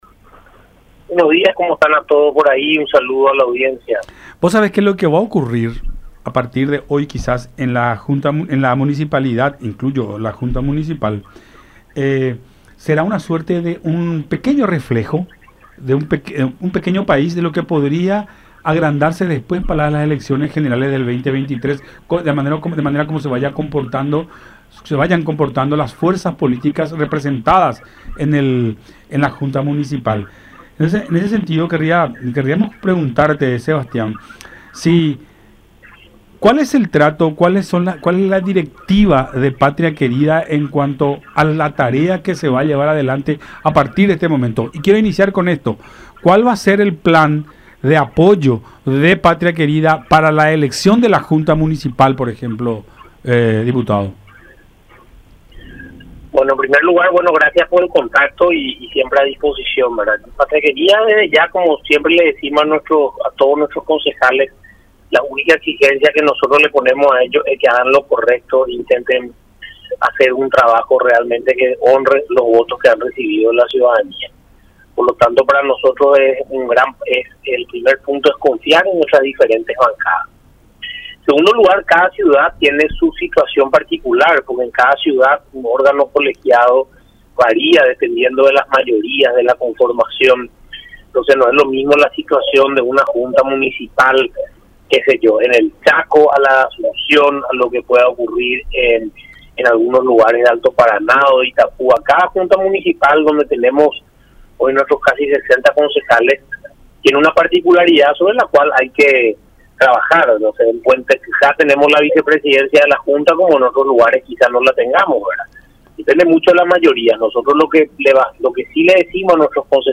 Tiene que controlar, cumplir un papel prudente y que esté atenta a lo que ocurra”, dijo Villarejo en diálogo con Enfoque 800 por La Unión.